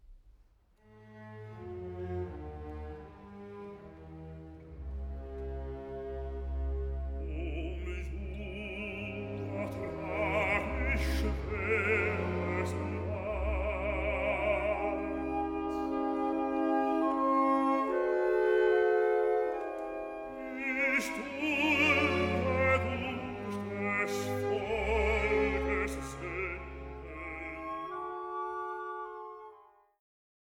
Rezitativ (Hanna)